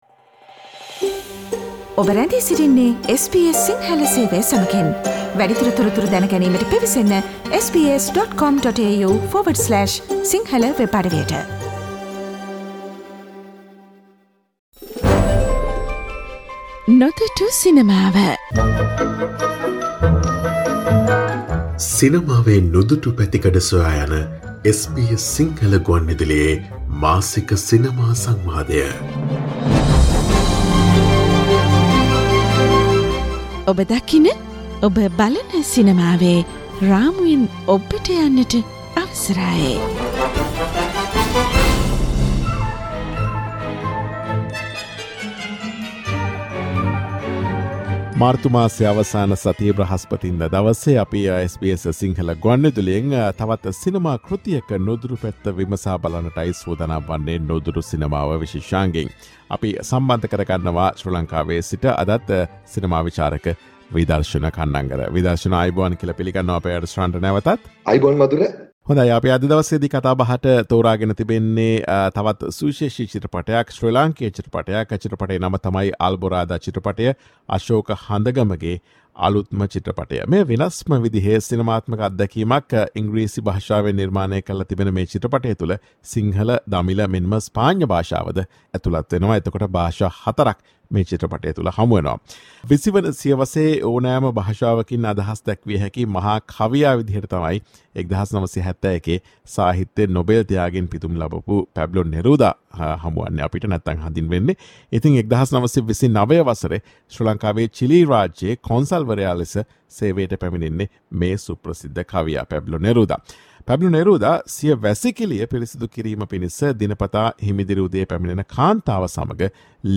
SBS සිංහල ගුවන් විදුලියේ මාසික සිනමා විශේෂාංගය වන "නුදුටු සිනමාවෙන්" මෙවර 20 වන සියවසේ මහා කවියා ලෙස විරුදාවලිය ලත් පැබ්ලෝ නෙරුදා ශ්‍රී ලංකාවේ ගත කළ විට කාන්තාවකට සිදුකළ ලිංගික බලහත්කාරකමක් අළලා නිර්මාණය වූ "ඇල්බොරාදා" චිත්‍රපටය පිළිබඳ විමසා බැලීමක්.